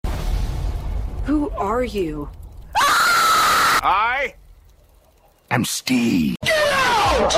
I Am Steve Scream Get Out - Bouton d'effet sonore